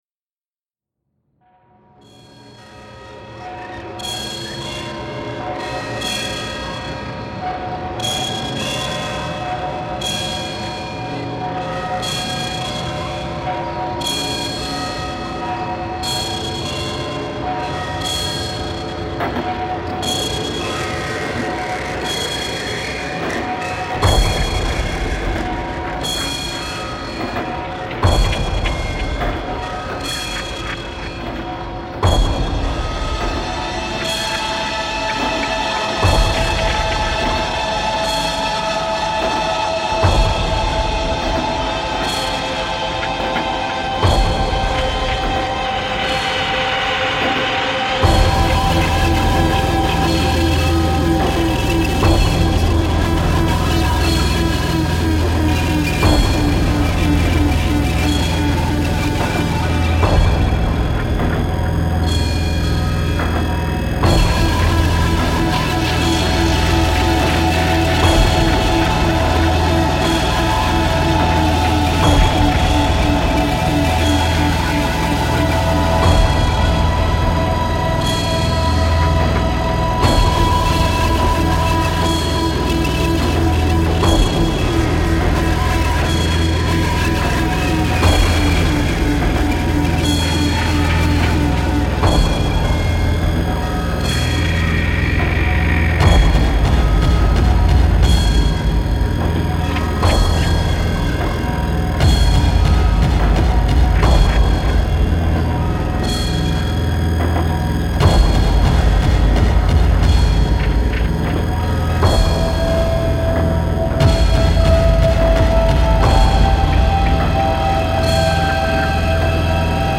Helsinki bells reimagined